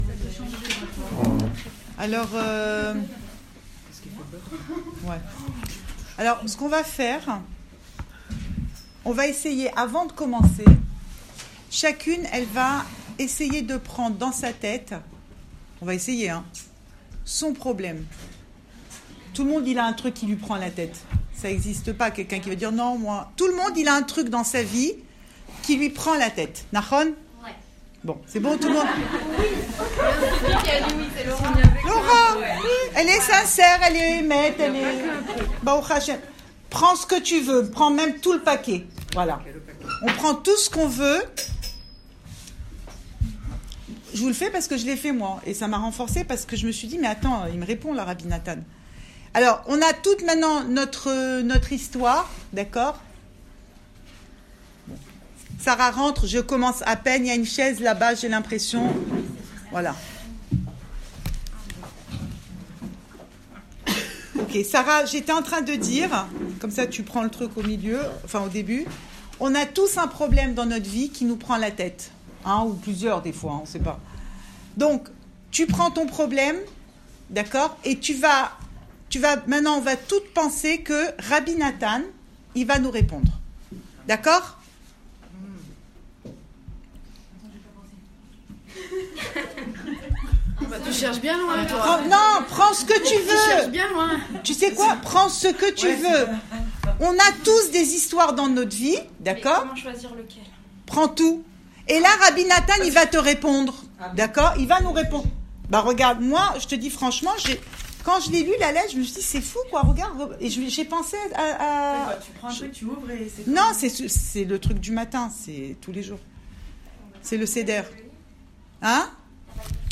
Cours audio Le coin des femmes Pensée Breslev - 8 janvier 2019 10 janvier 2019 Qu’est ce qui me rend dingue ? Enregistré à Raanana